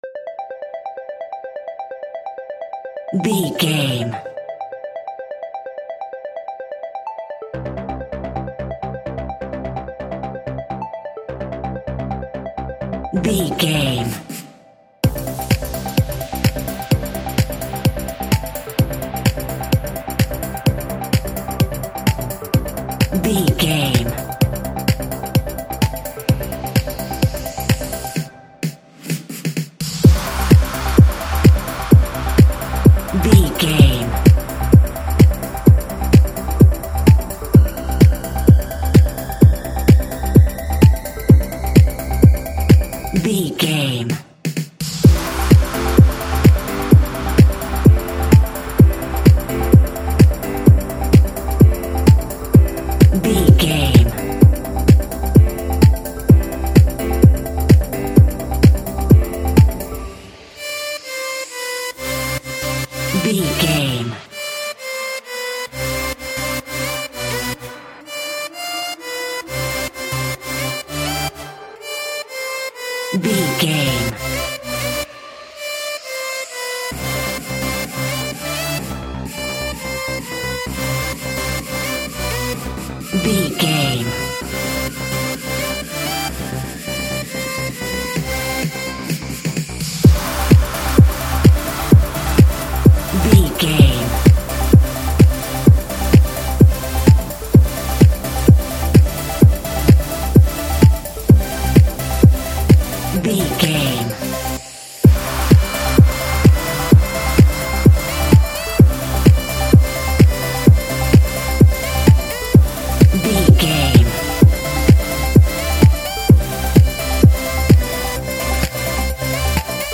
Ionian/Major
Fast
energetic
hypnotic
uplifting
synthesiser
drum machine
uptempo
synth leads
synth bass